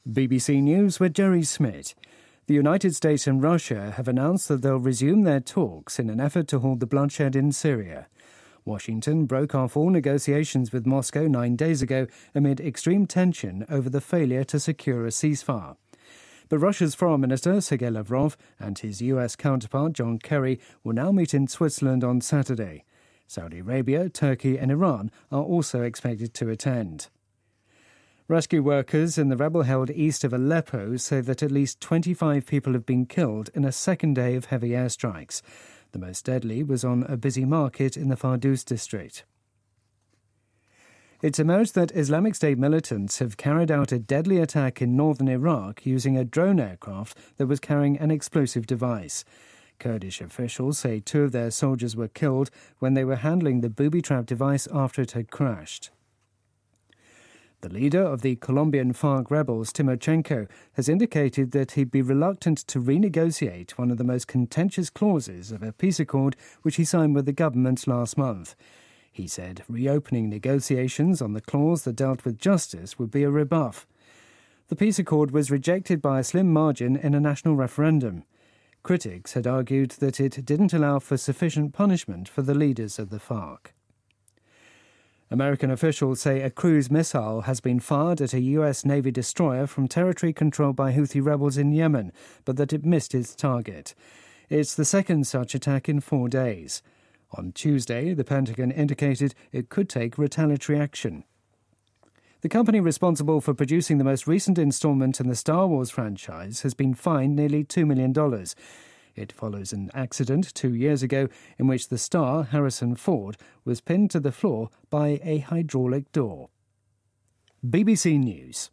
BBC news,美俄就叙利亚问题重回谈判桌
日期:2016-10-14来源:BBC新闻听力 编辑:给力英语BBC频道